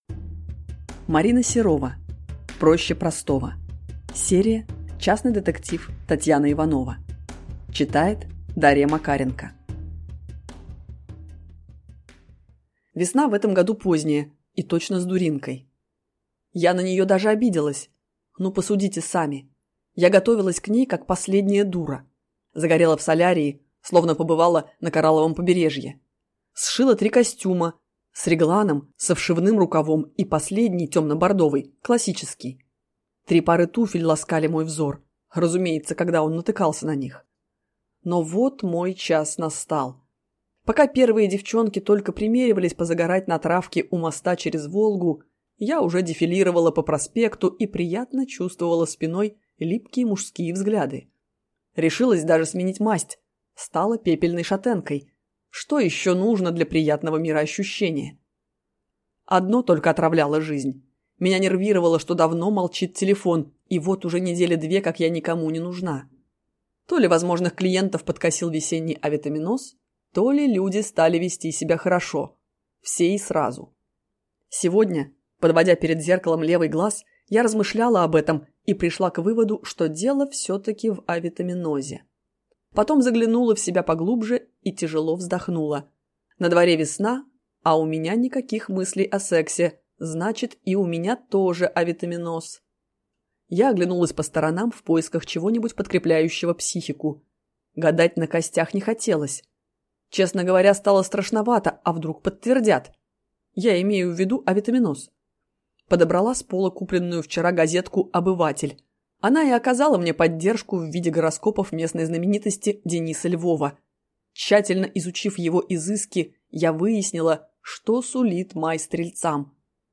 Аудиокнига Проще простого | Библиотека аудиокниг
Прослушать и бесплатно скачать фрагмент аудиокниги